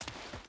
Footsteps / Dirt
Dirt Walk 3.wav